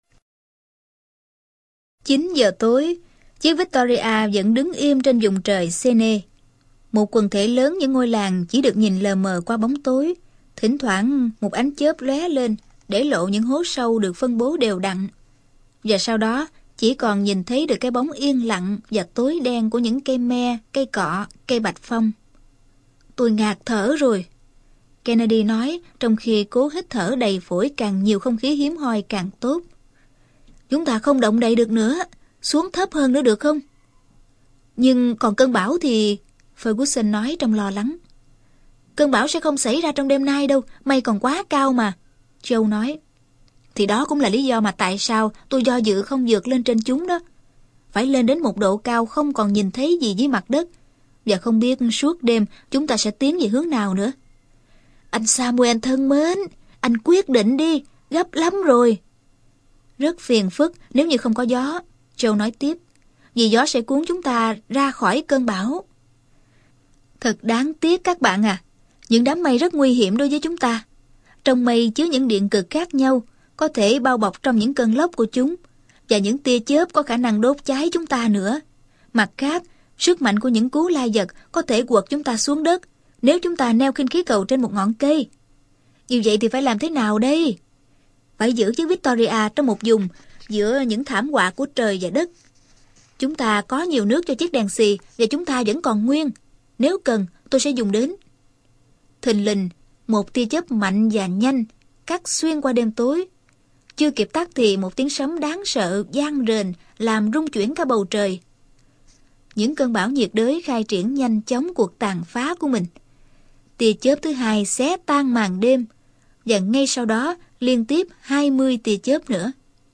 Sách nói 5 tuần trên khinh khí cầu - Jules Verne - Sách Nói Online Hay